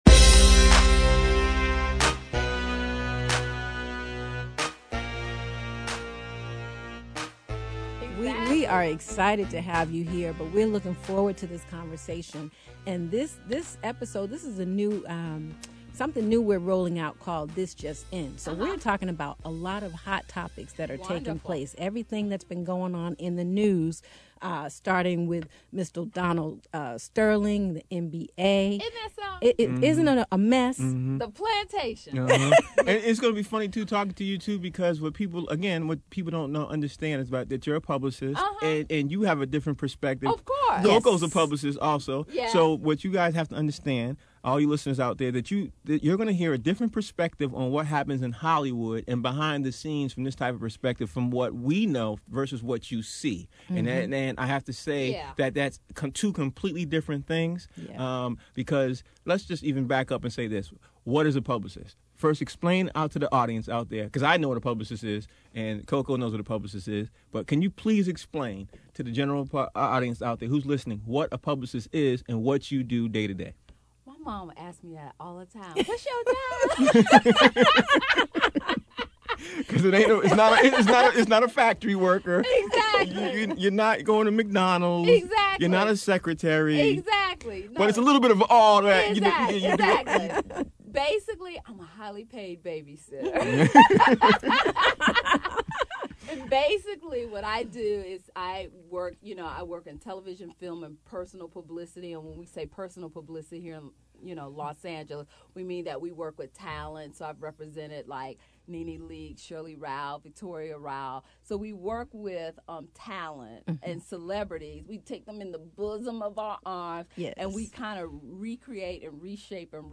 As “Clippergate” and Los Angeles Clipper owner Donald Sterling continue to dominate headlines, and really put a spotlight on institutionalized racism in our society, I took my rants to the airwaves of “Flow Therapy” on Humor Mill radio on Friday, May 2, 2014. Oh, I had plenty of jokes for Donald Sterling. Check out my interview below.